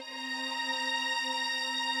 Under Cover (Strings) 120BPM.wav